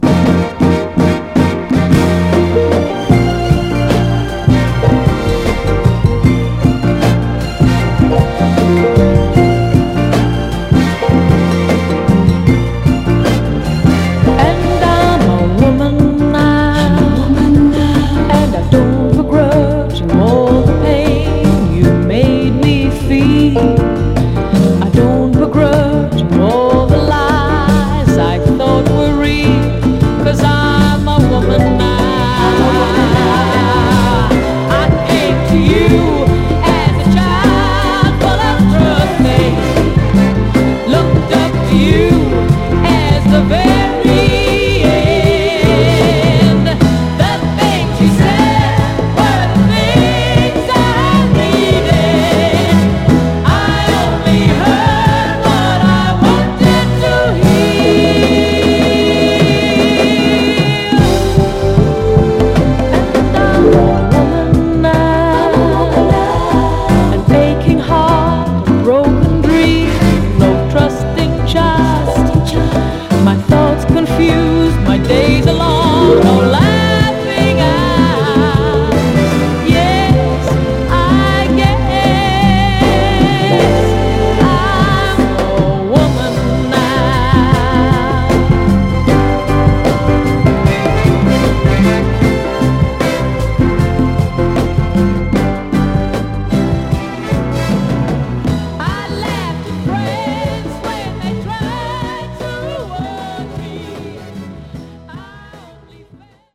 完成度の高いメロウ・ノーザン/クロスオーヴァー・ソウル
盤はいくつか細かいスレ箇所ありますが、グロスがありプレイ良好です。
※試聴音源は実際にお送りする商品から録音したものです※